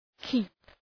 keep Προφορά
{ki:p}